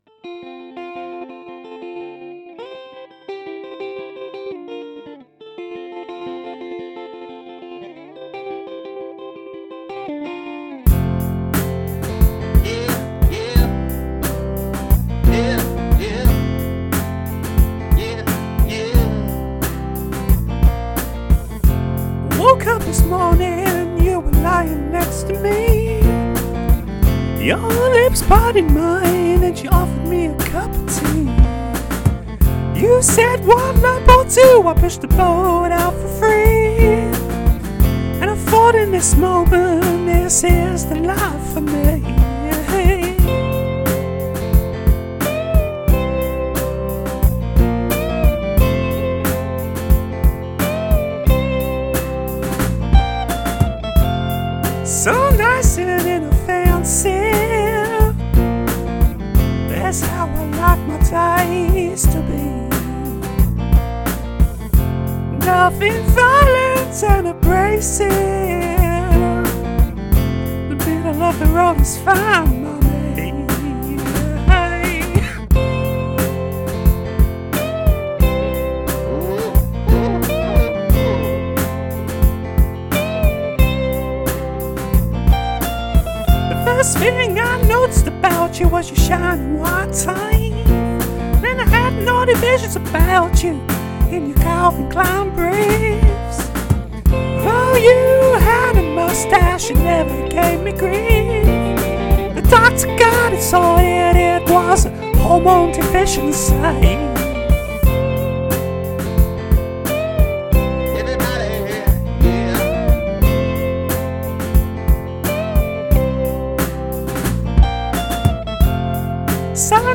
coffee table-friendly chord sequence